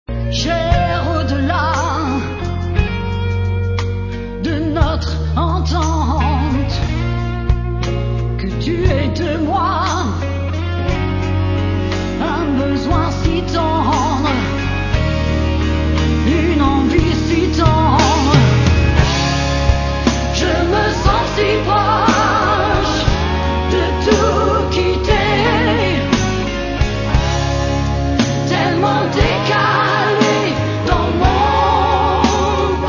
ihre gekonnte Mischung aus Rock, Blues und Chanson
Aufnahme mit 5kB/s, fs=16kHz Hörprobe